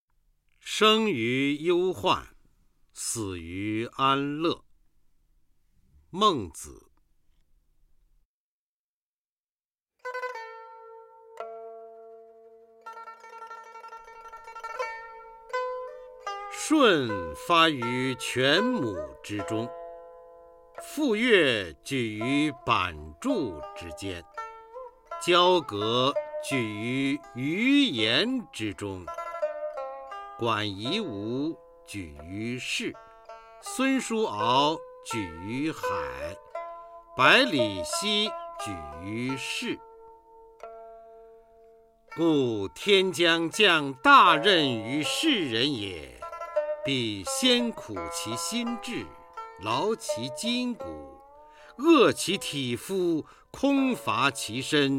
初中生必背古诗文标准朗诵（修订版）（1）-03-方明-生于忧患 死于安乐 《孟子》战国 孟轲